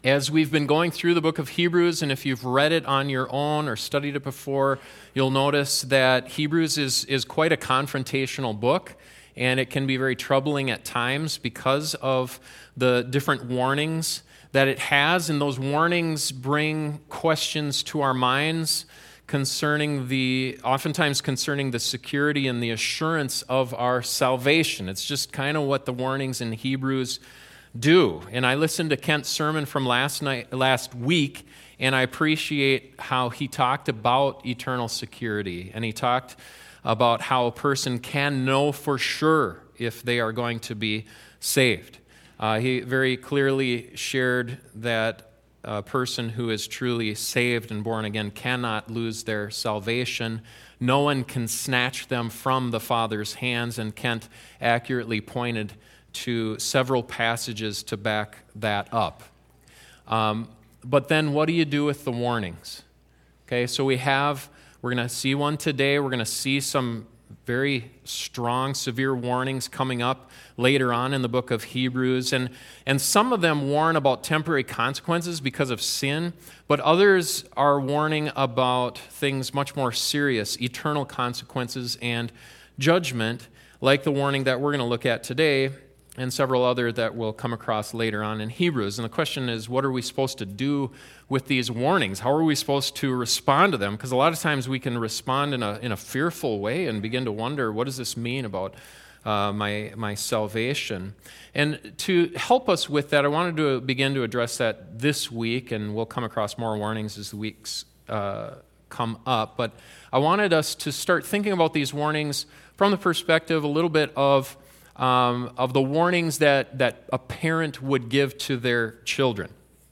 The book of Hebrews contains many warnings that can be confrontational and troubling, including the warning covered in this sermon. How are we to understand and respond to these warnings?